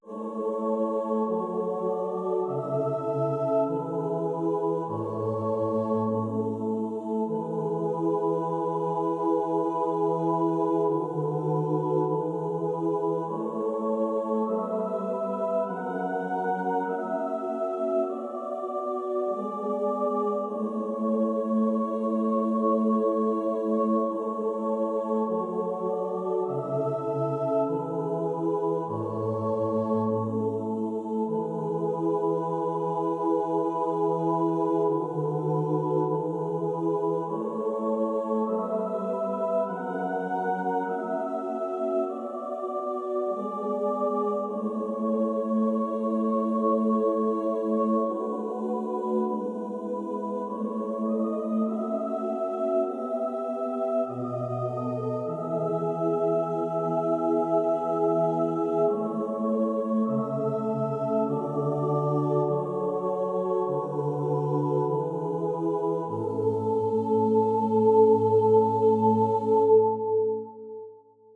4-Part Chorales that aren’t Bach
Comments: The use of minor v in m. 6 is striking and an example of mode mixture.
A short chain of secondary dominants occurs in the third phrase leading to a tonicized half cadence.